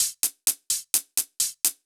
Index of /musicradar/ultimate-hihat-samples/128bpm
UHH_ElectroHatA_128-05.wav